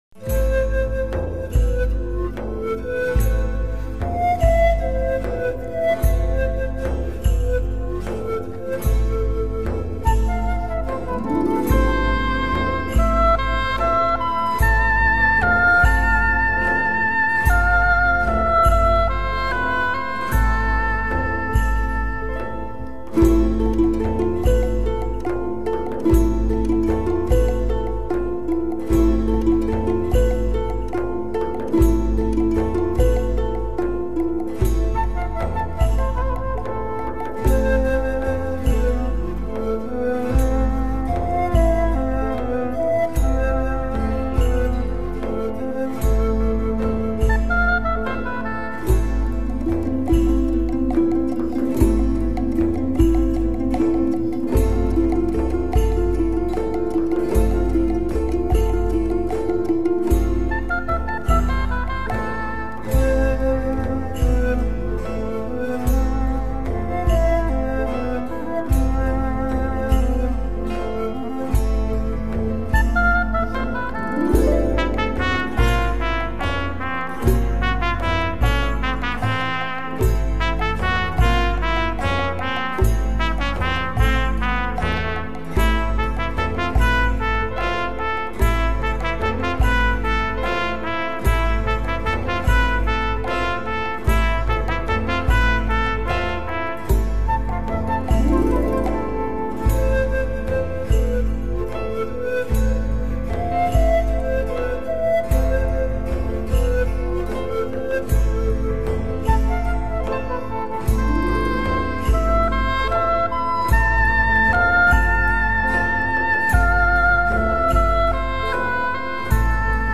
Ancient Greek Music.mp3